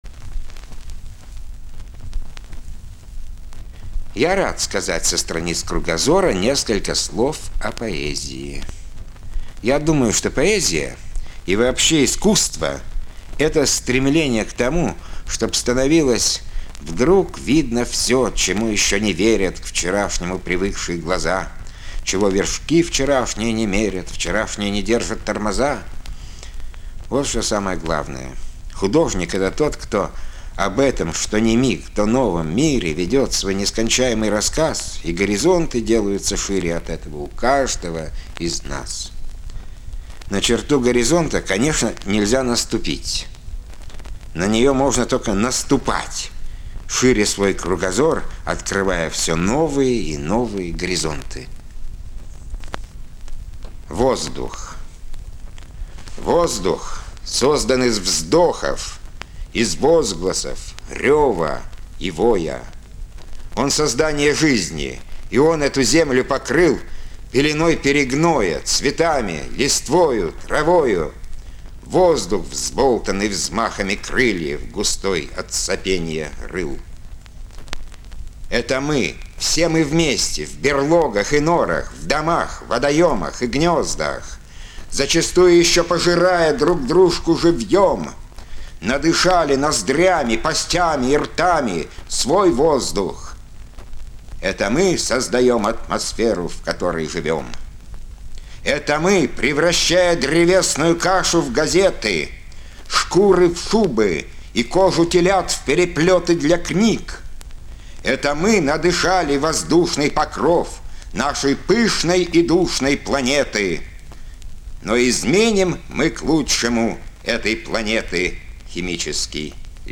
На третьей звуковой странице Леонид МАРТЫНОВ обращается к слушателям и читателям журнала 'Кругозор' и продолжает поэтический рассказ.
Звуковая страница 3 - У микрофона 'Кругозора' поэт Леонид Мартынов.